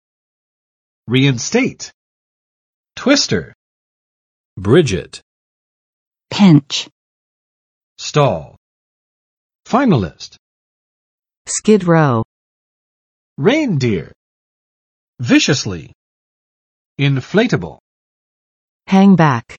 [͵riɪnˋstet] v. 使复原；使恢复